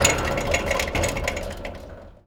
metal_rattle_spin_med_03.wav